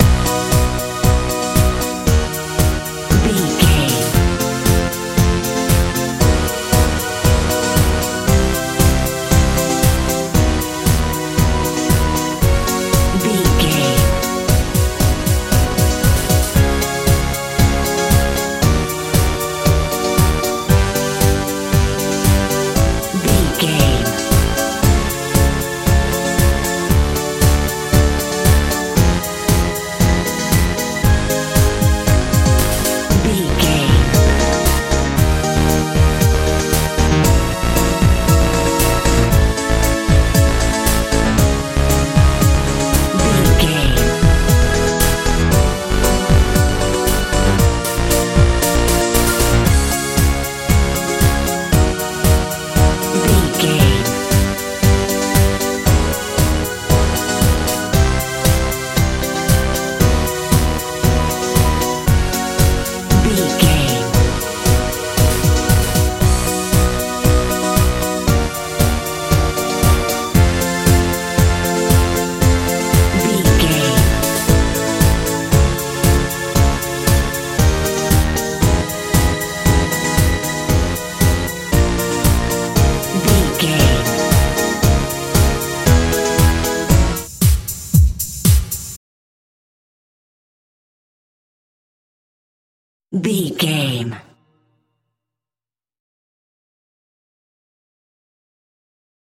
dance feel
Ionian/Major
groovy
synthesiser
bass guitar
drums
80s
90s